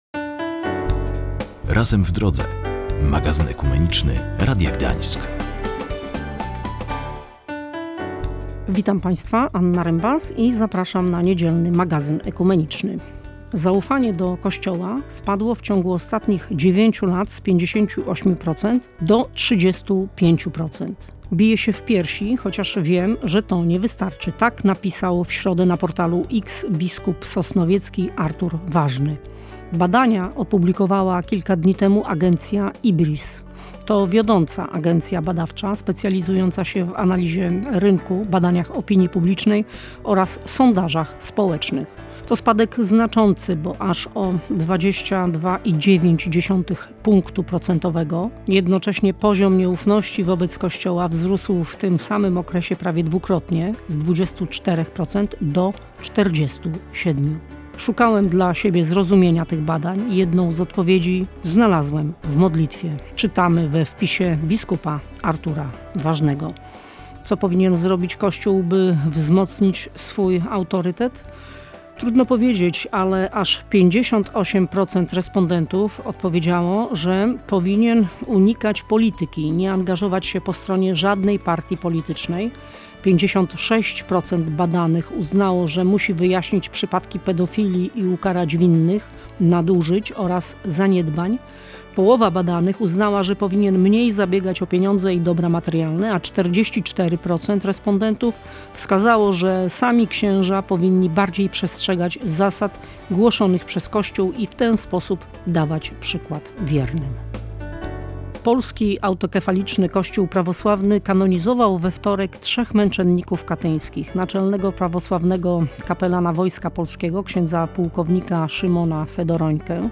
ROZMOWA Z KS.